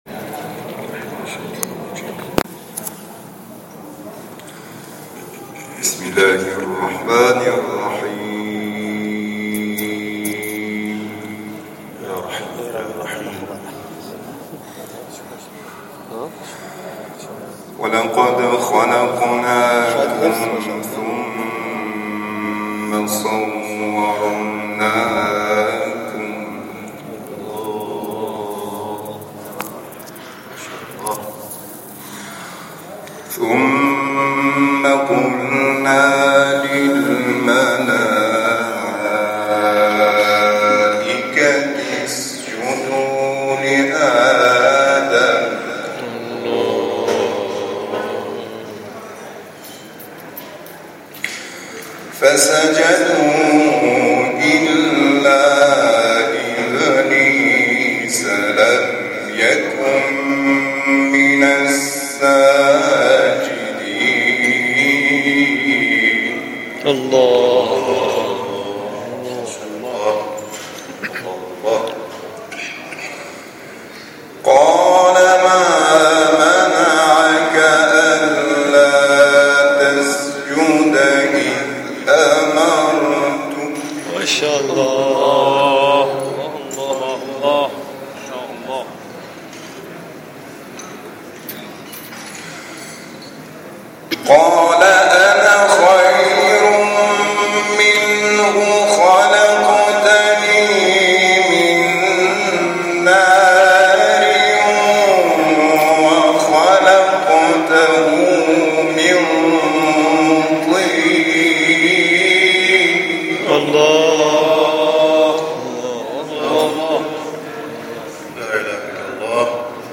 تلاوت قاریان مهمان و ابتهال